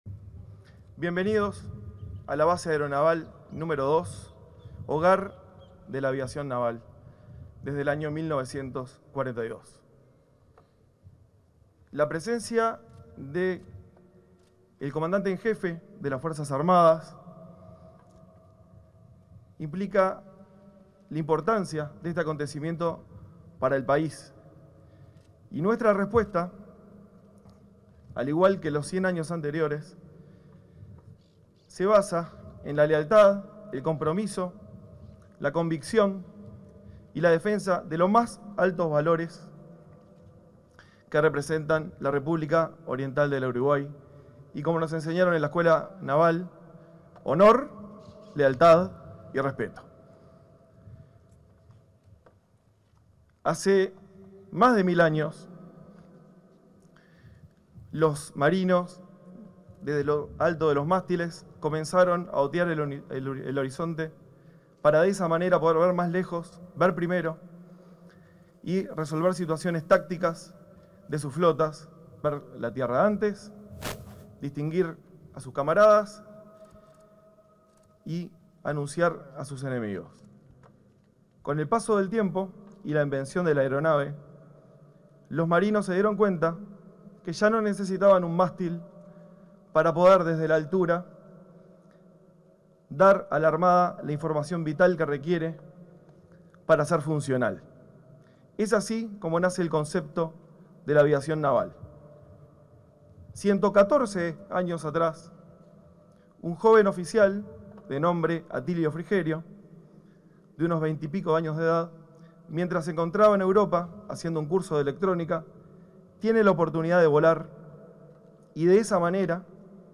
Palabras del comandante de la Aviación Naval, Nicolás Sanguinetti 07/02/2025 Compartir Facebook X Copiar enlace WhatsApp LinkedIn En el marco de la ceremonia conmemorativa del centenario de la creación de la Aviación Naval, este 7 de febrero, se expresó el comandante de esa fuerza, Nicolás Sanguinetti.